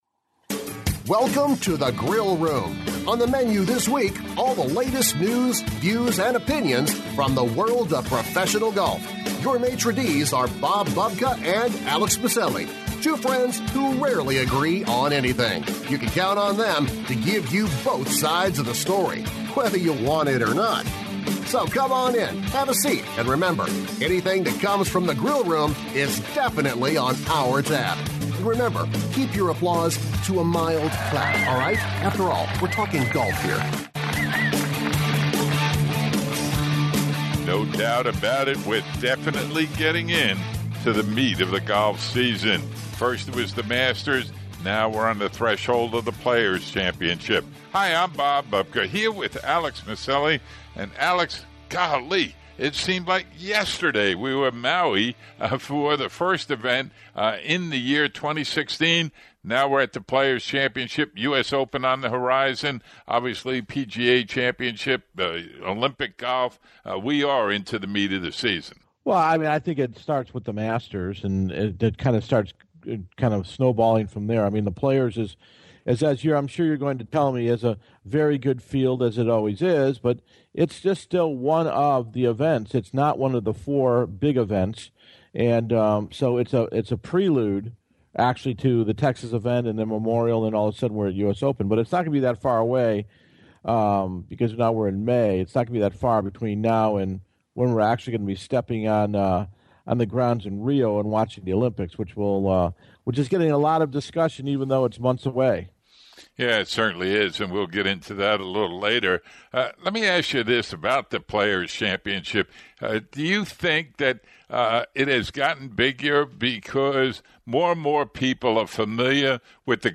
We hear from Hahn, Mickelson, McIlroy and even from Jesper Parnevik who won the PGA TOUR Champions Insperity Invitational in The Woodlands, TX.